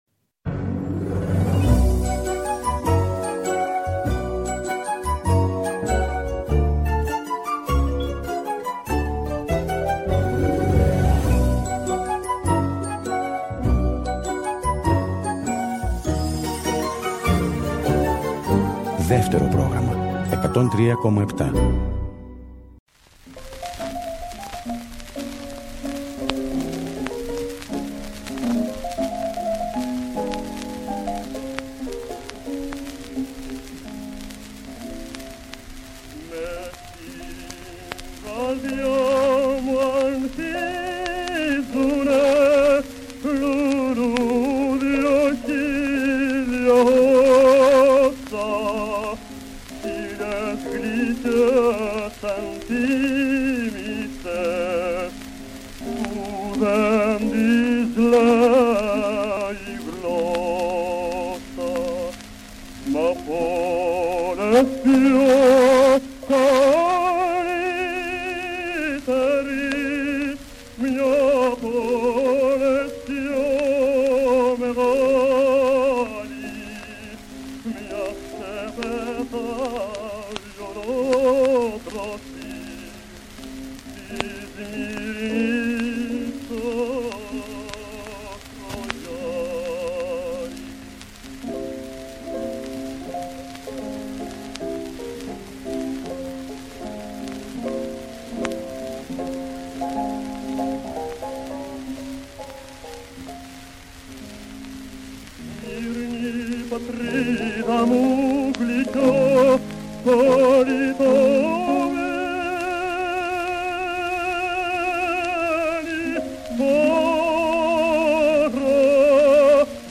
με τραγούδια και κείμενα για τη Σμύρνη. Η πρώτη εκπομπή εστιάζει στο ελαφρό και η δεύτερη στο λαϊκό ρεπερτόριο. Μεταδόθηκαν για πρώτη φορά το 1982.